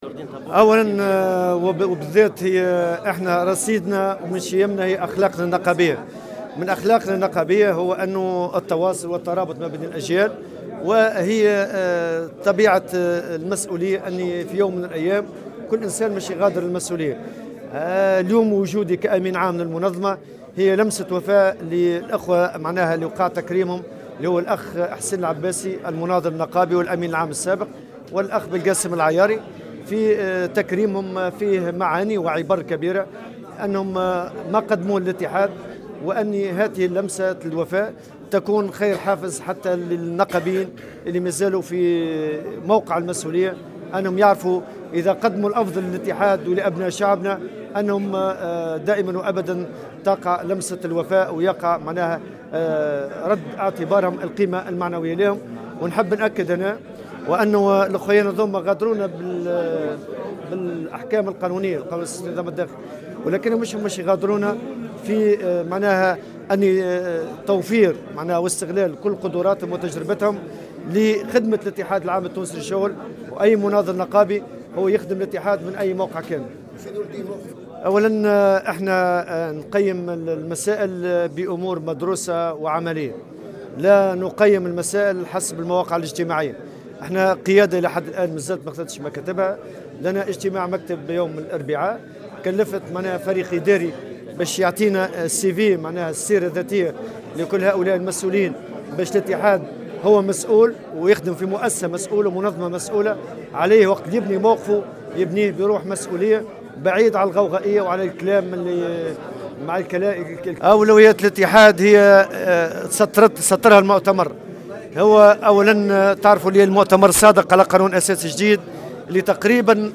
أكد الأمين العام للإتحاد العام التونسي للشغل نورالدين الطبوبي في تصريح للجوهرة اف ام" اليوم الإثنين على هامش تكريم إدارة مصنع التبغ بالقيروان للأمين العام السابق وأعضاء المكتب التنفيذي أن أجور العاملين في القطاع الخاص ستكون من أوكد أولويات الإتحاد في المرحلة المقبلة .